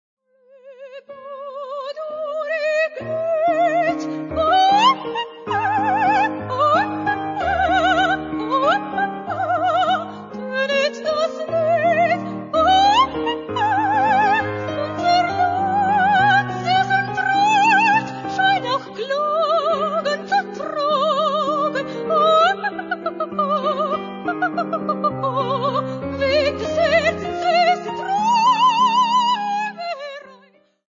Coloratur Sopranistin
Bariton